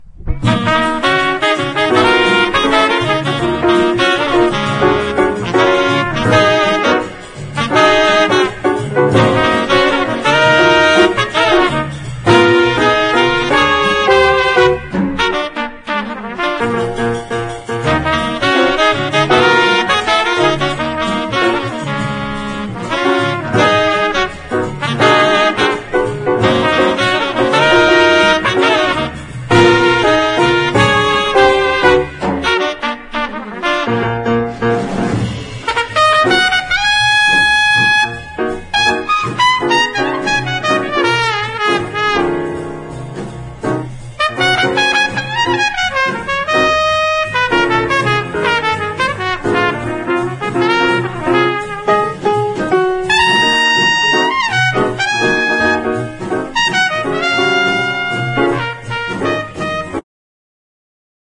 ネタとしても楽曲としても名曲揃いのメロウ・アルバム！